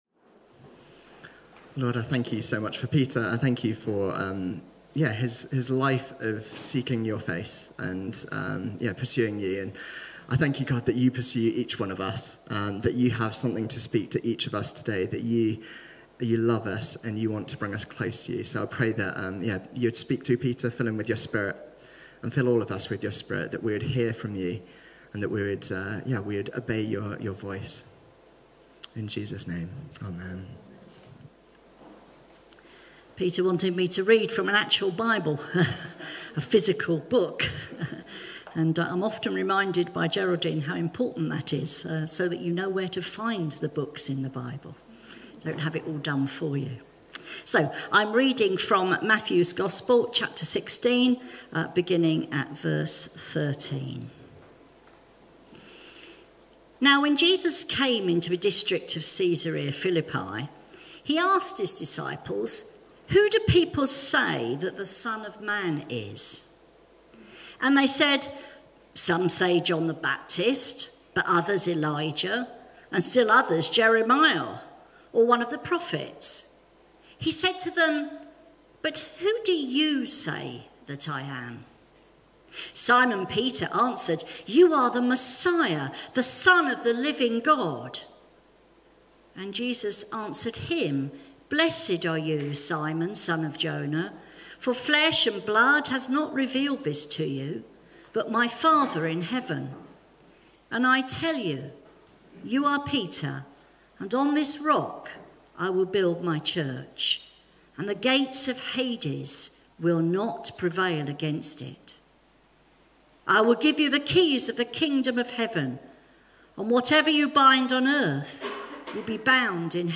Religion Highgrove Church Bristol Sea Sermons Highgrove Church Christianity Mill Talk Content provided by Highgrove Church.